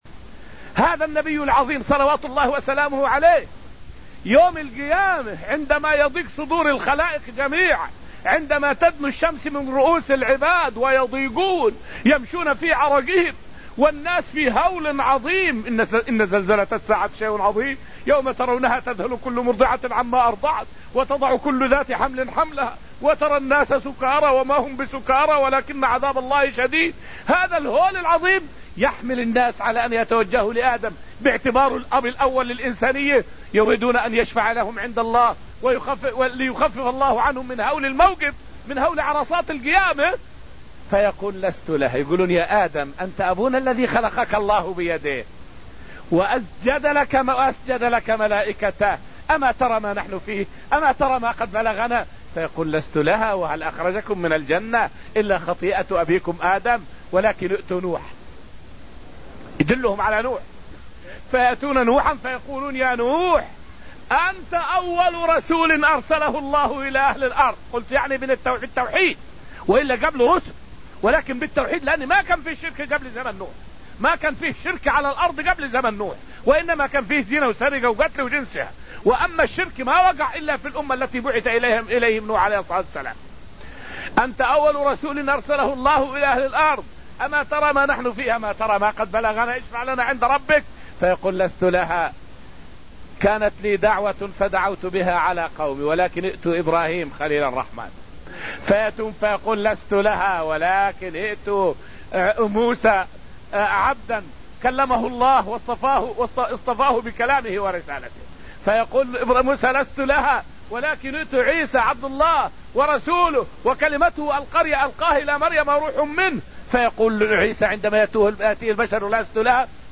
فائدة من الدرس السابع من دروس تفسير سورة الحديد والتي ألقيت في المسجد النبوي الشريف حول شفاعة النبي للخلائق يوم القيامة.